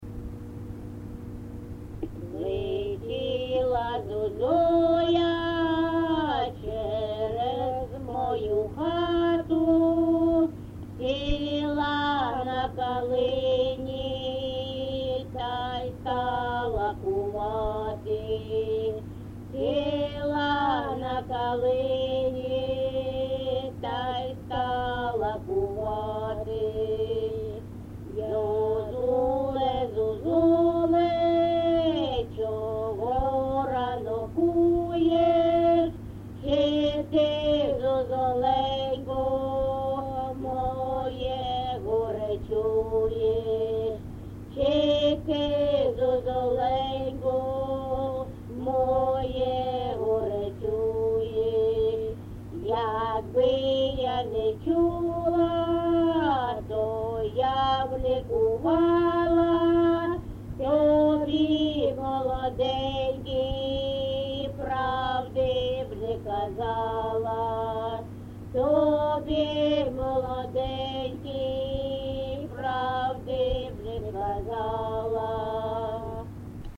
ЖанрПісні з особистого та родинного життя
МотивНещаслива доля, Журба, туга
Місце записус. Гнилиця, Сумський район, Сумська обл., Україна, Слобожанщина